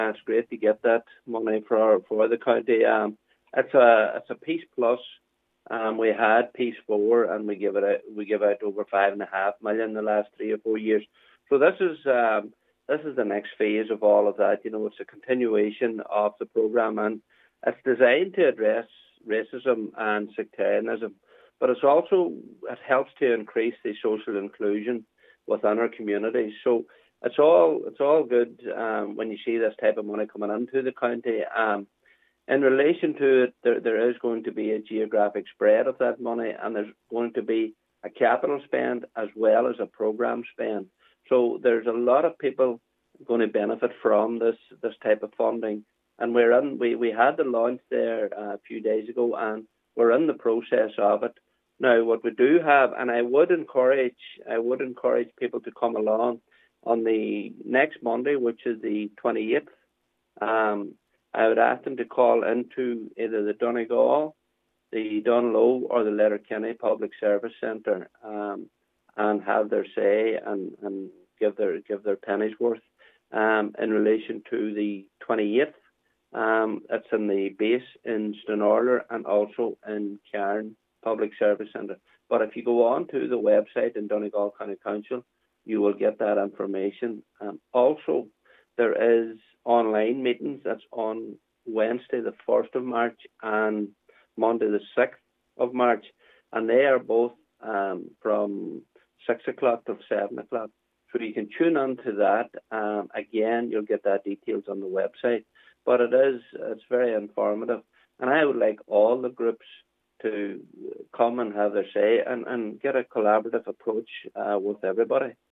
Cllr Paul Canning, Chair of the PEACEPLUS Partnership says the funding is significant and will help enhance social inclusion in Donegal: